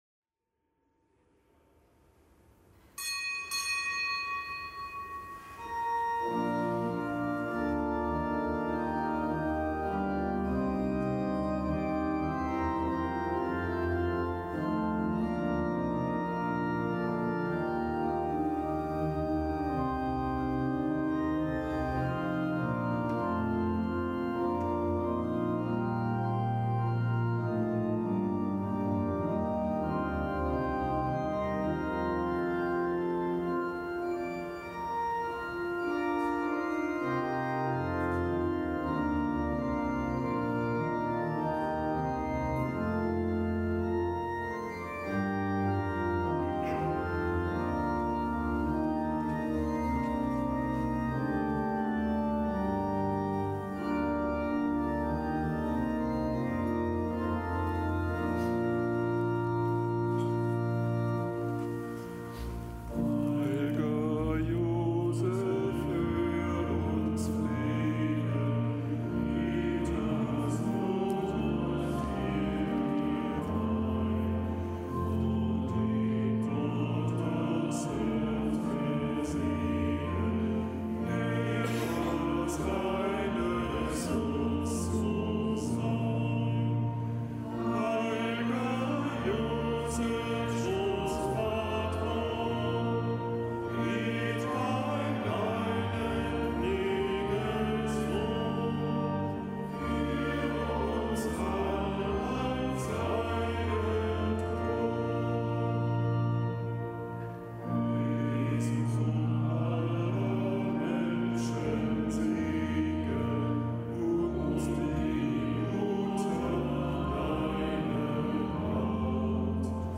Kapitelsmesse aus dem Kölner Dom am Hochfest des Heiligen Josef, Bräutigam der Gottesmutter Maria und Schutzpatron der Erzdiözese. Zelebrant: Weihbischof Ansgar Puff.